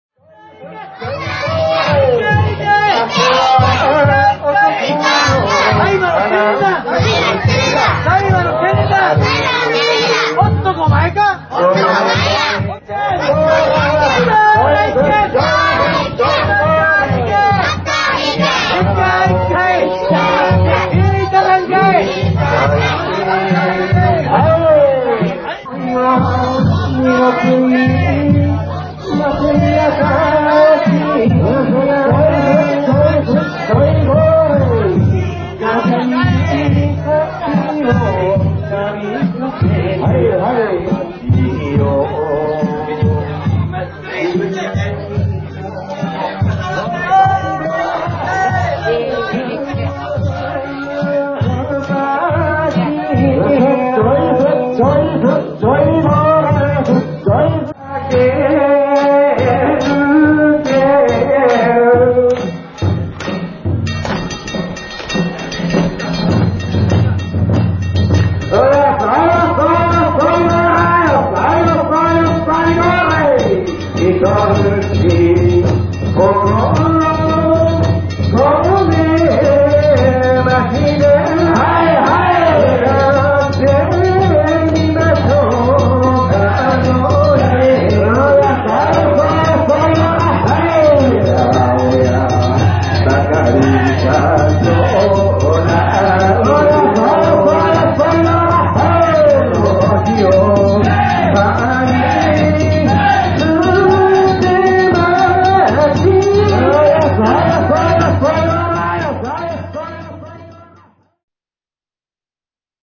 平成２８年３月２０日、奈良県葛城市の當麻地車お披露目曳行を見に行ってきました。
曳き歌が始まりました。
お兄ちゃんの掛け声に合わせて子供たちも大きな声を出してます。
綱先は曳き唄とはシンクロせず綱先独自で掛け声かけてます♪
曲調が変わりました♪
曳き唄どんどん変えながらお披露目曳行が続きます。